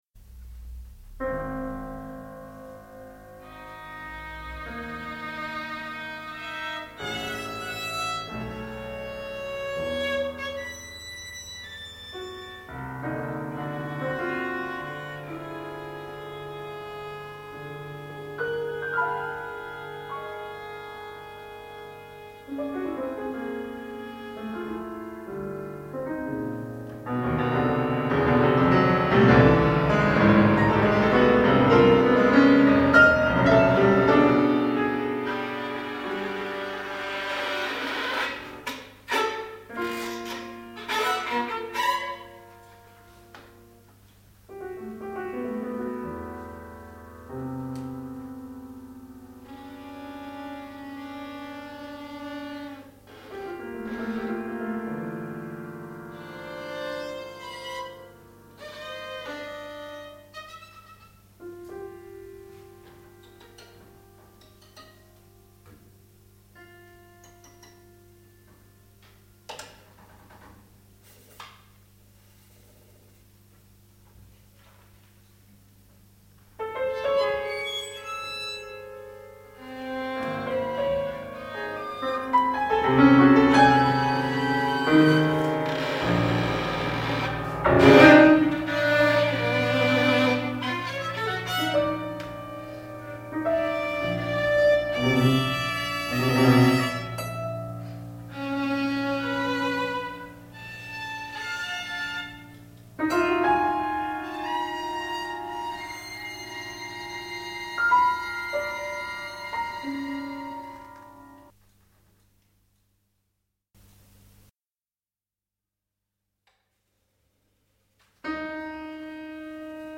1991-93)f�r Violine und Klavier